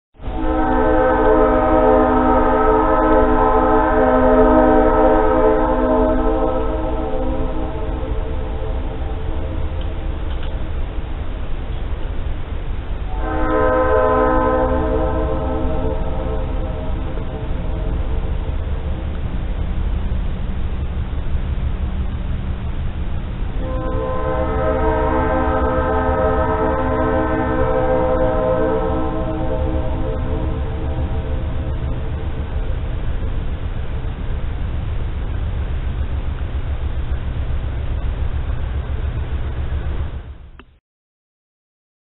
دانلود آهنگ سوت قطار 3 از افکت صوتی حمل و نقل
دانلود صدای سوت قطار 3 از ساعد نیوز با لینک مستقیم و کیفیت بالا
جلوه های صوتی